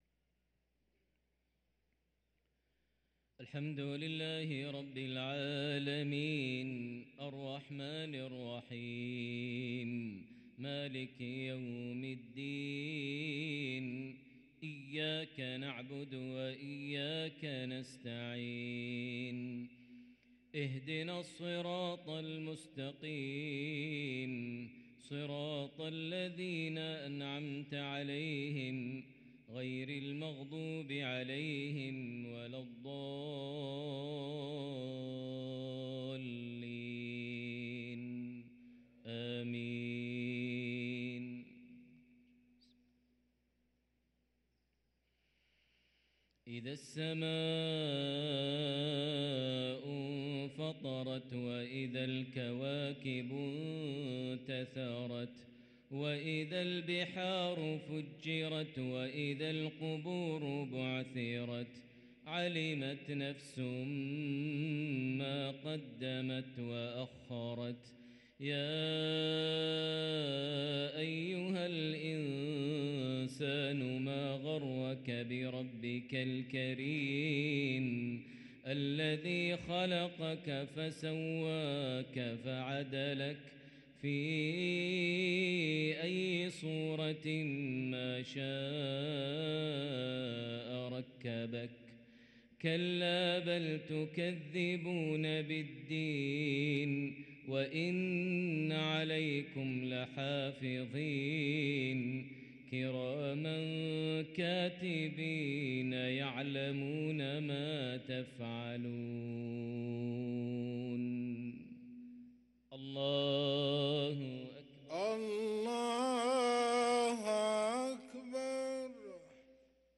صلاة المغرب للقارئ ماهر المعيقلي 28 جمادي الآخر 1444 هـ
تِلَاوَات الْحَرَمَيْن .